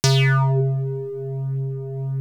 JD SYNTHA1.wav